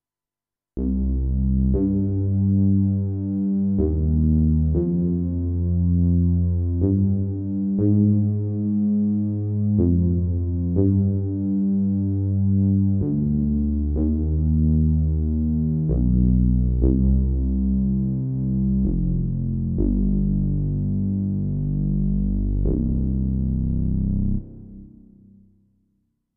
14. I SUONI - GLI STRUMENTI XG - GRUPPO "BASS"
31. Rubber Bass
XG-04-31-RubberBass.mp3